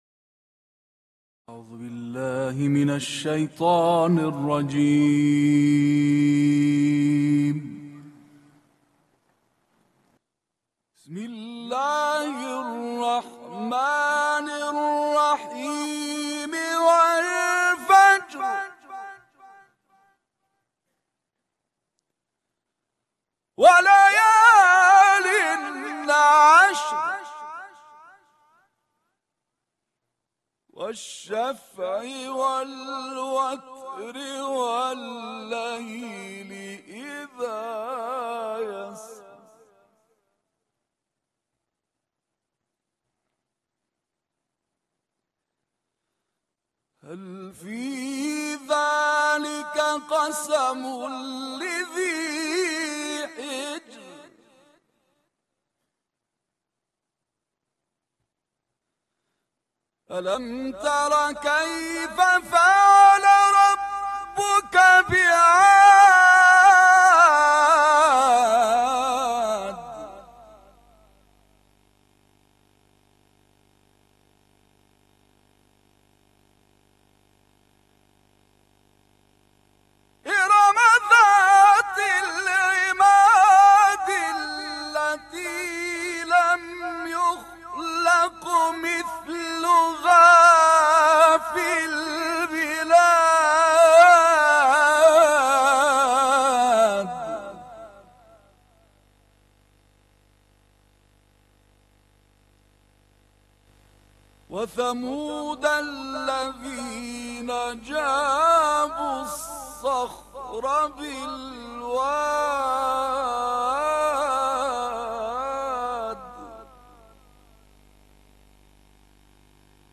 Тиловати таҳқиқи
Тиловати маҷлисии сураи Фаҷрро